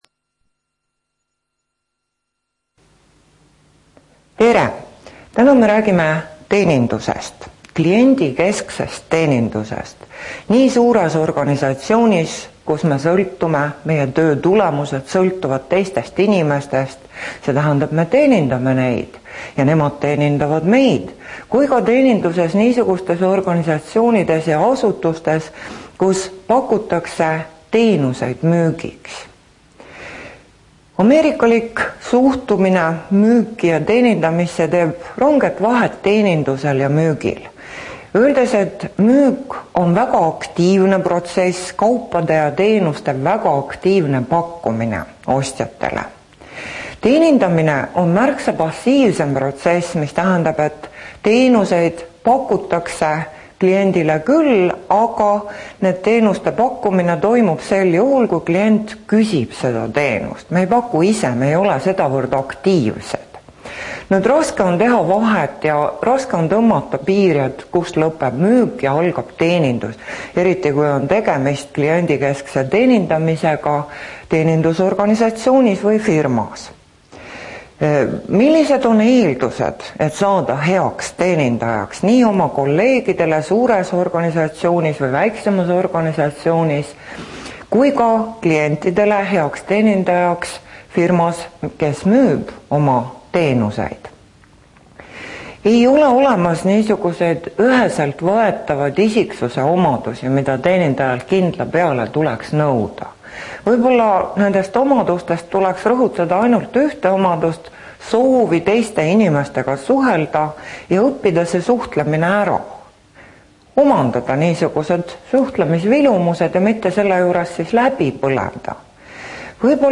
Teenindamine loengu MAPP printimiseks Mapi lugemiseks vajate programmi Adobe Acrobat Reader kui teil seda arvutis ei ole siis leiate selle siit Teenindamine loeng MP3 failina (26MB)